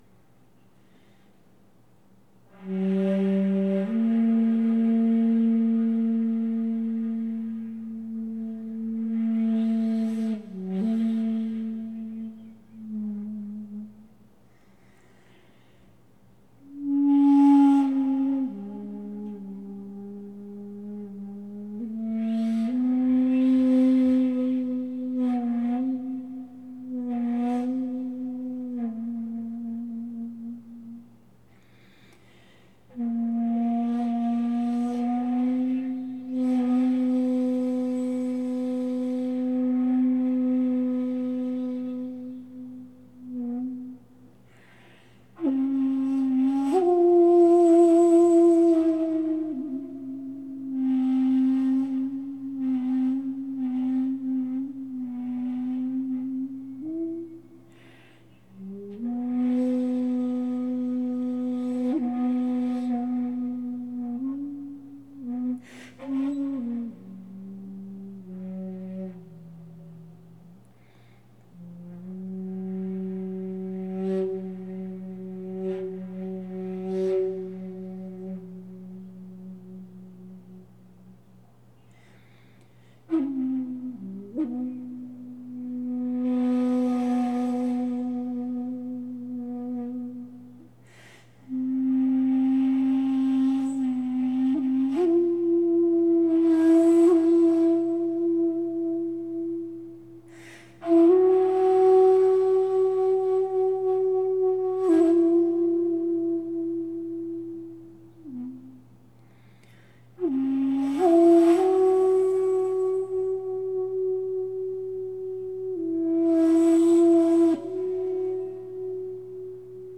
Инструменты:
"Сегодняшняя" импровизация